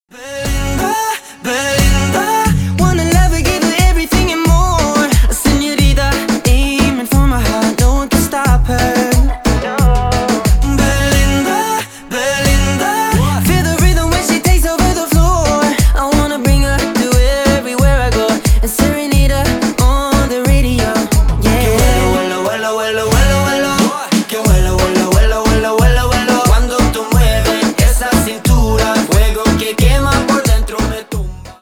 Поп Музыка
латинские # клубные